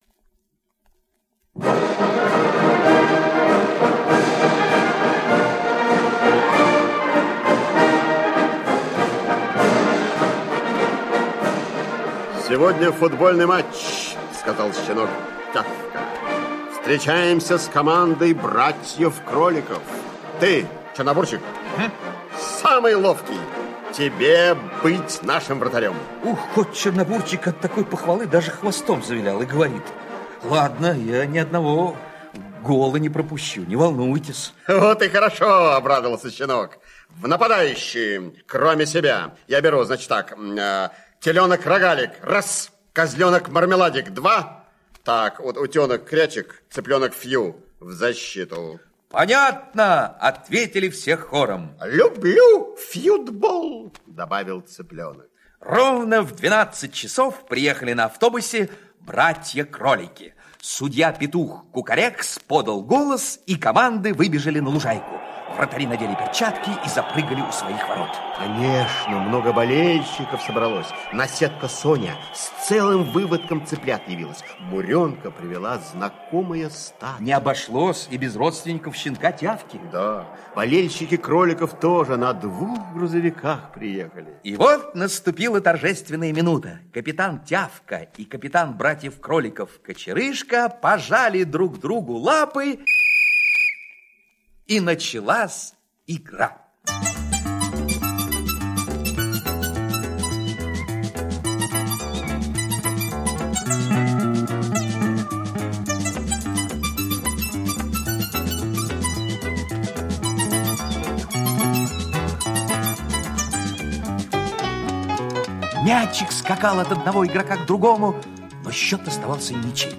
Аудиосказка «Как Чернобурчик в футбол играл»